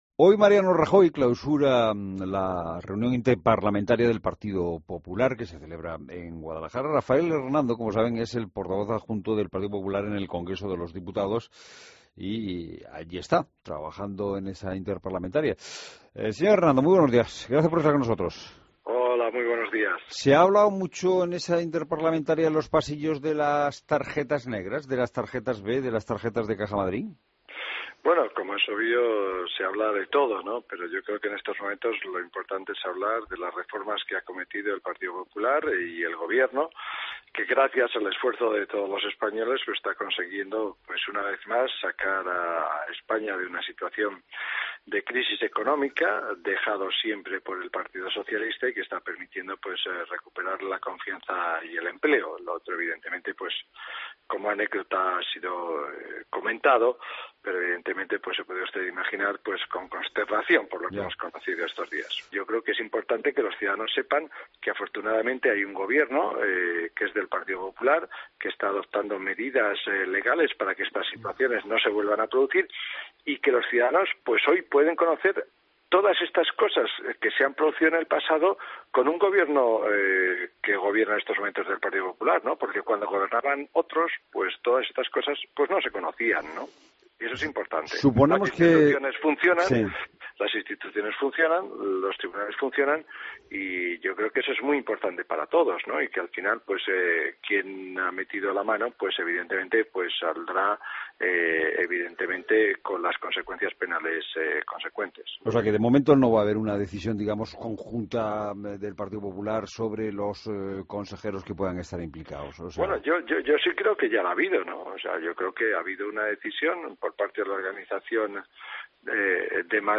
Entrevista La Mañana COPE: Rafael Hernando, portavoz PP Congreso